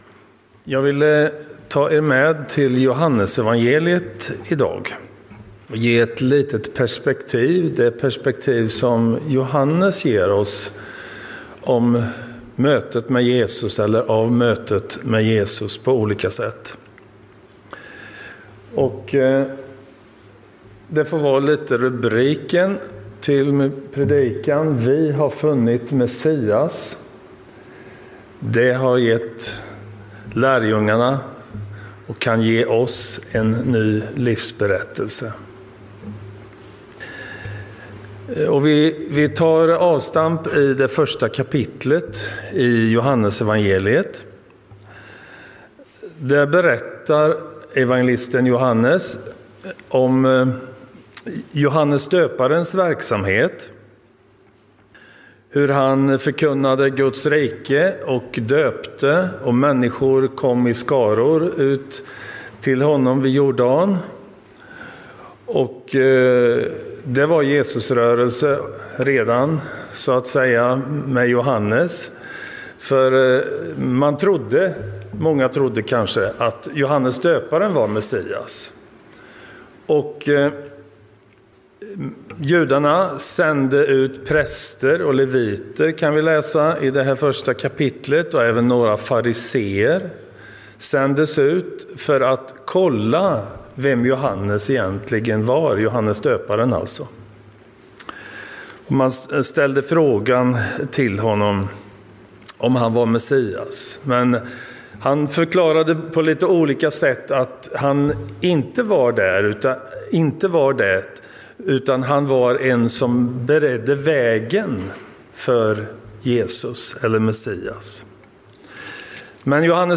Gudstjänst i Centrumkyrkan i Mariannelund